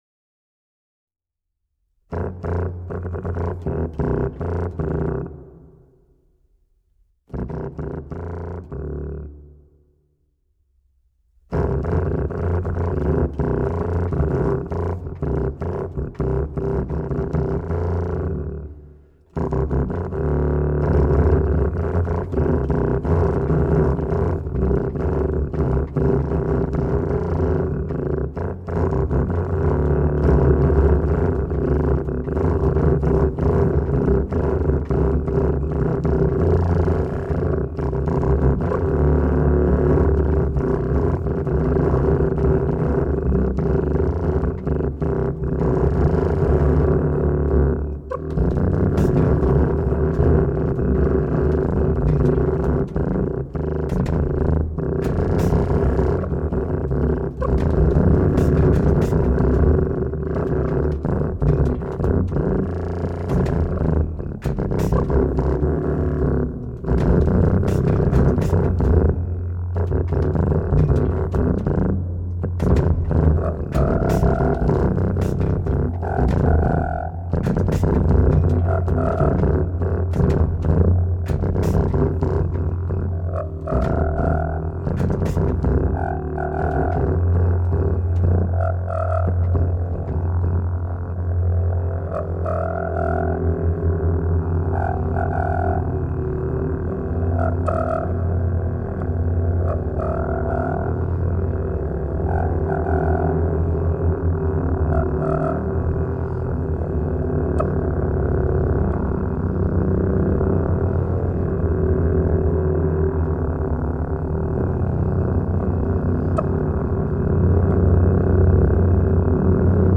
tuba